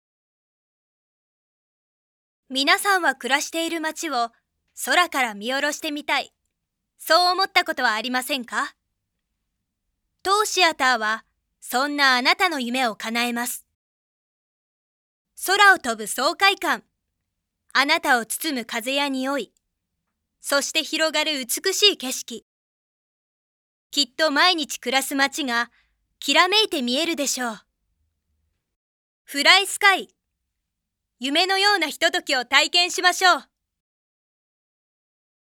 ボイスサンプル
女騎士
少年１
少年２
真面目な女の子
大人のお姉さん
調子のよい女友達
ナレーション